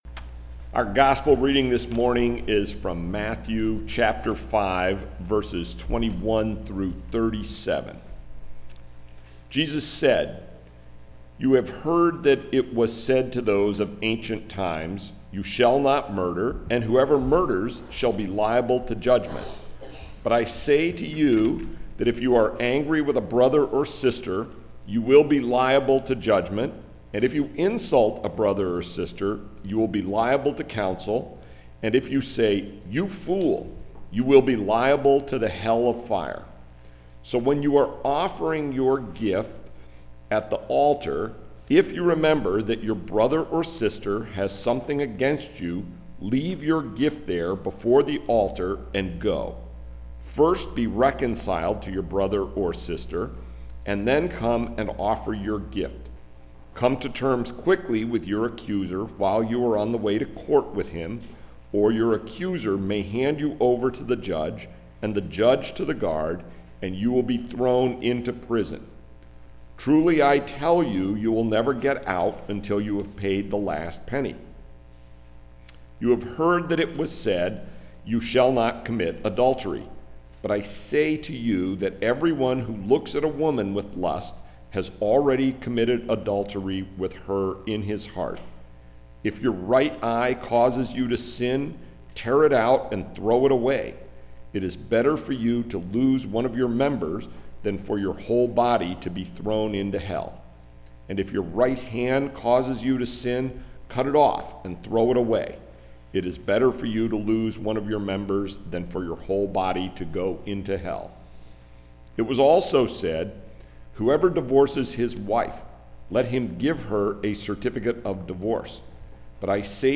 Message Delivered at: The United Church of Underhill (UCC and UMC)